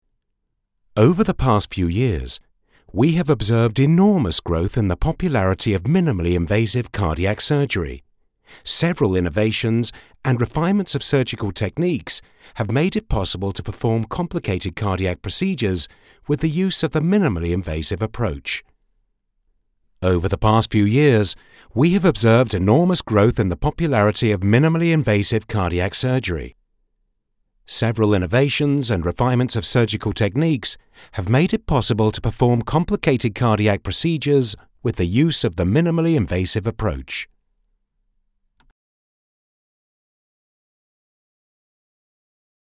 Male
English (British)
Adult (30-50), Older Sound (50+)
An award winning intelligent warmth and soft English style that will arrest your ears with vocal charisma and educated credibility.